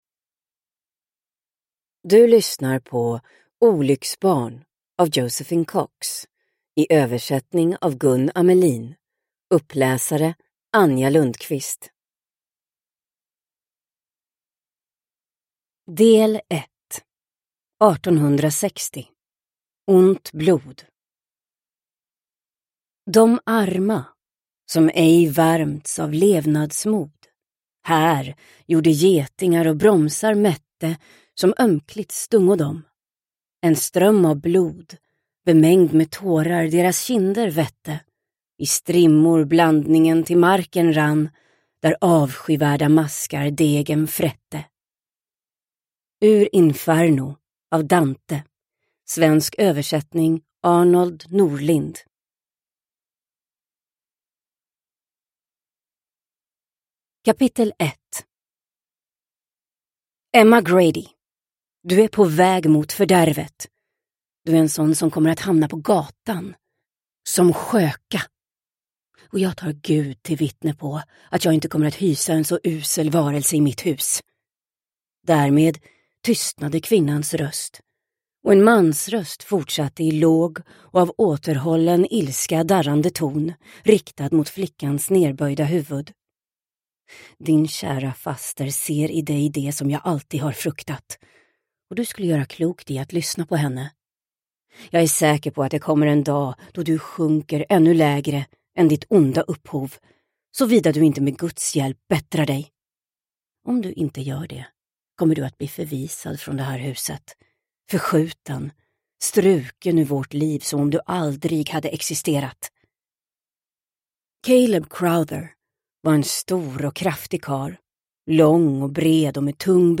Olycksbarn – Ljudbok – Laddas ner